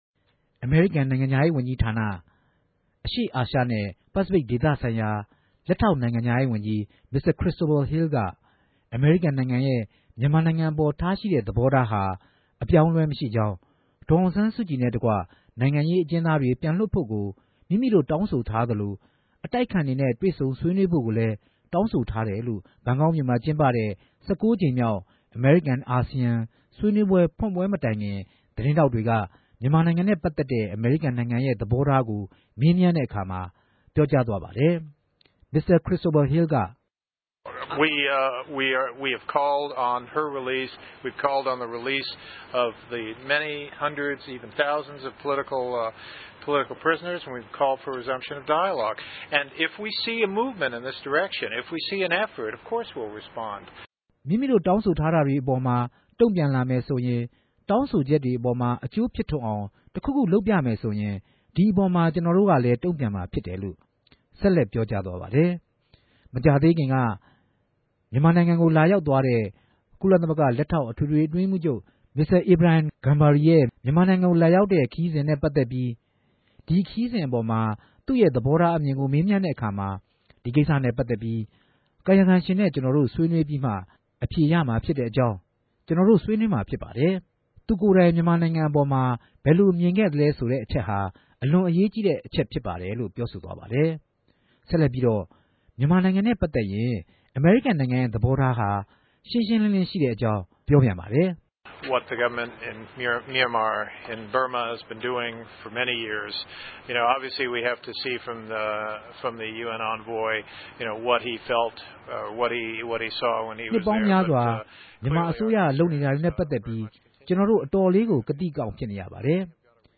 ူမန်မာိံိုင်ငံက စစ်အစိုးရ အနေနဲႛ စစ်မြန်တဲ့ ိံိုင်ငံရေးေူပာင်းလဲမြတြေ လုပ်ဆောင်လာအောင် ိံိုင်ငံတကာအသိုင်းအဝိုင်းအနေနဲႛ ဆက်လက် ဖိအားပေး ဆောင်႟ြက်သြားရမြာ ူဖစ်တယ်လိုႛ အမေရိကန်လက်ထောကိံိုင်ငူံခားရေးဝန်ဋ္ဌကီး ခရစ်စတိုဖာ ဟီးလ်က ဗန်ကောက်္ဘမိြႚတော်မြာ သတင်းထောက်တေကြို ေူပာုကားခဲ့ပၝတယ်။